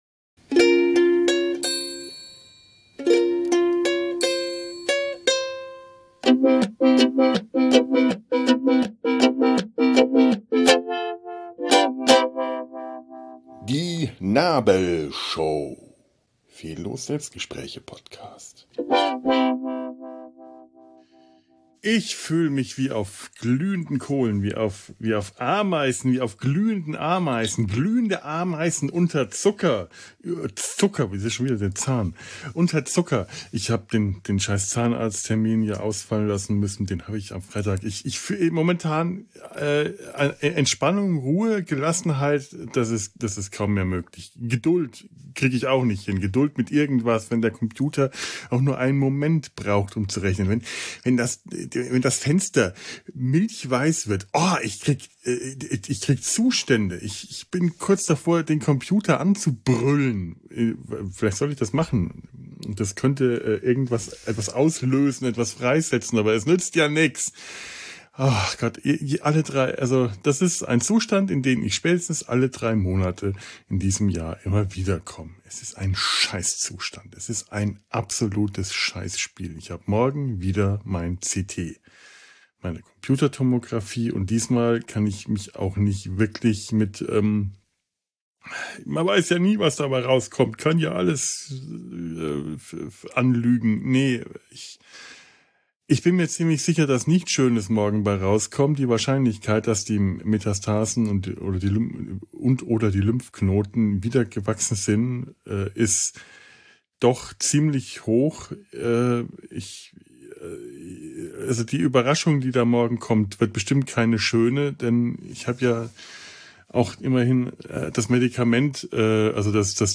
(Mit Ukulele-Klängen!)